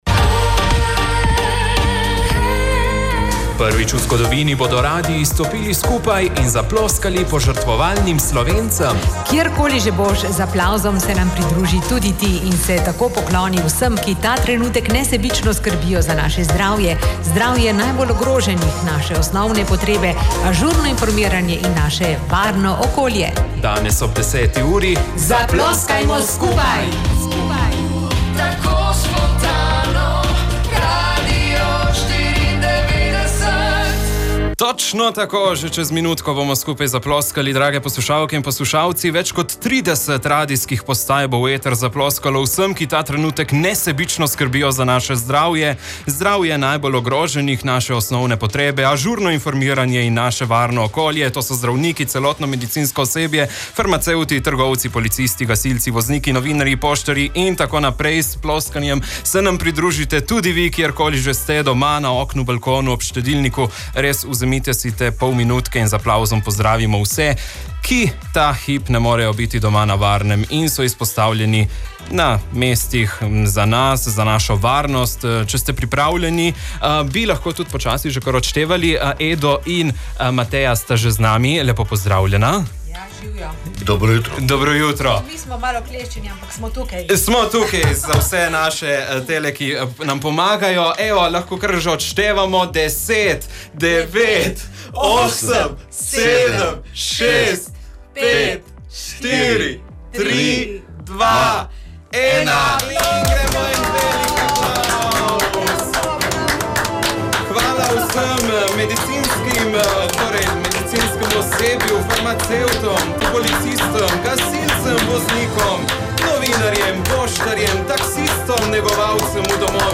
Več kot 30 radijskih postaj je danes ob 10. uri v eter zaploskalo vsem, ki ta trenutek nesebično skrbijo za naše zdravje, zdravje najbolj ogroženih, naše osnovne potrebe, ažurno informiranje in naše varno okolje.
Zahvaljujemo se vsem, ki ste se nam z aplavzom pridružili.
aplauz-radio94.mp3